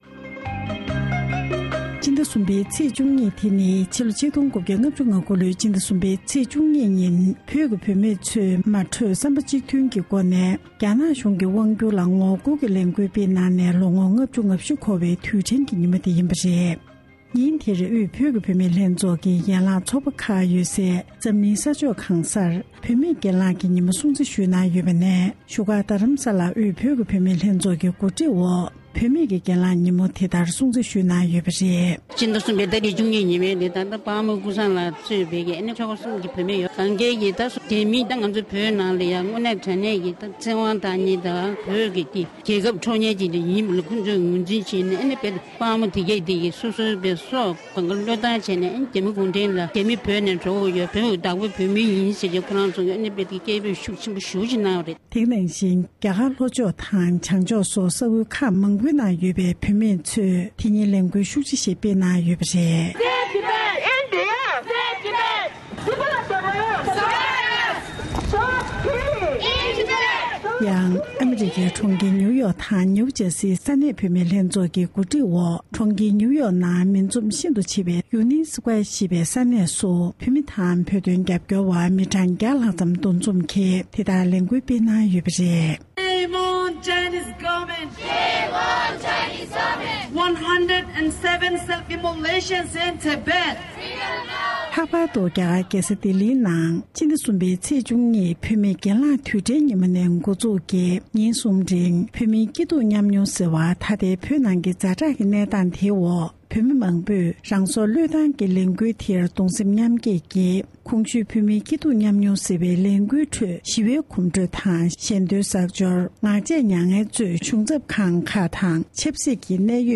འབྲེལ་ཡོད་མི་སྣར་གནས་འདྲི་ཞུས་པའི་དམིགས་བསལ་གྱི་ལས་རིམ་ཞིག